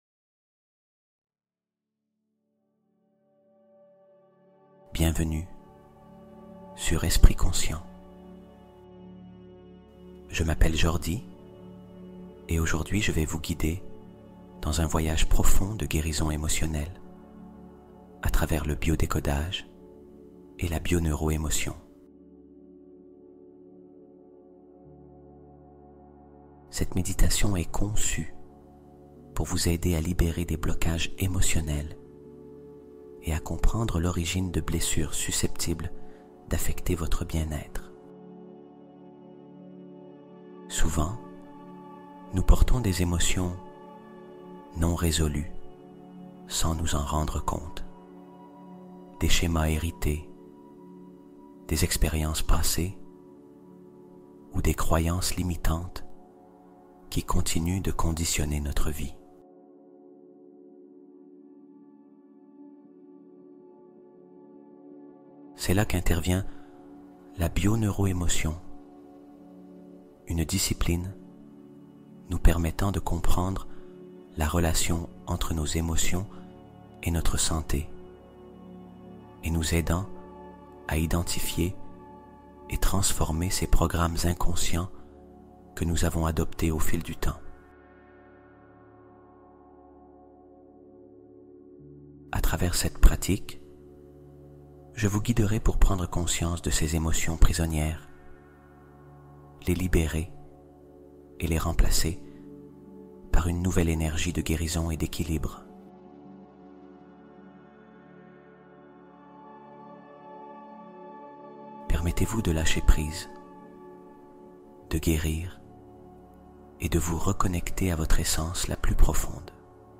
Hypnose profonde pour un sommeil réellement réparateur